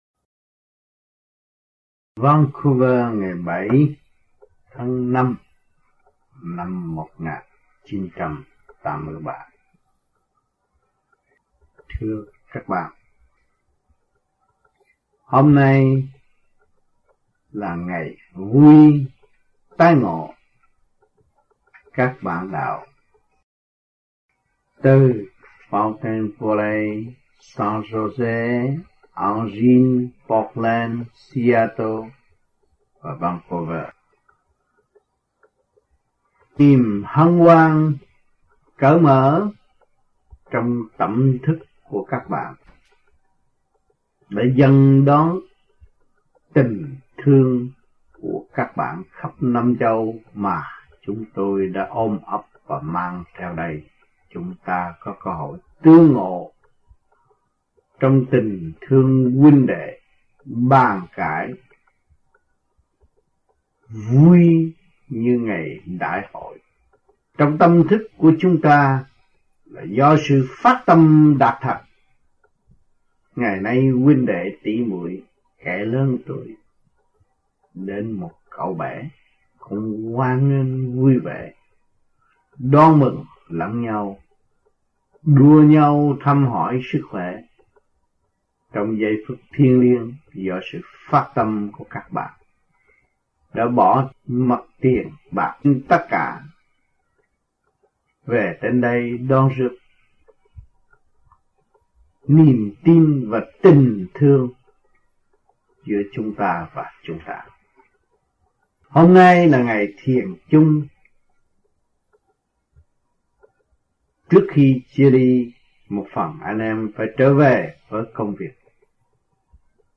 Băng Giảng